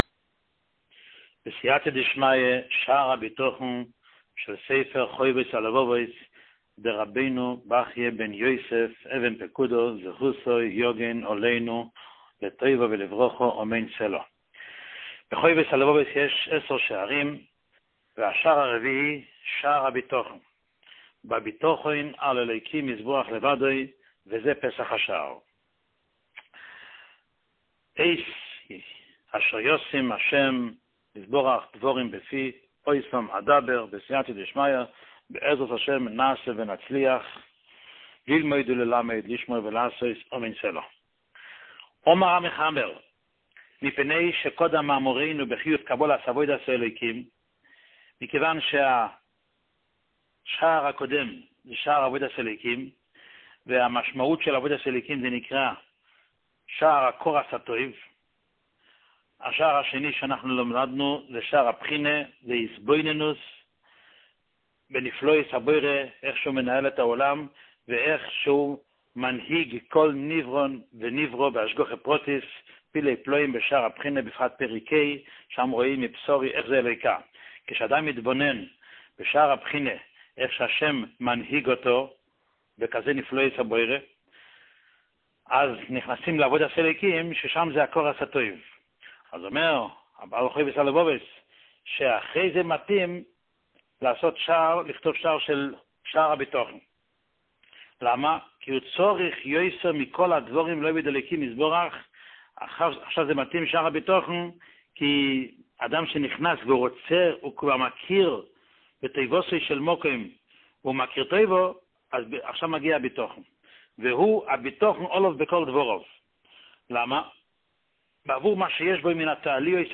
שיעור מספר 1